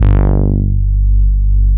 20 MOOG BASS.wav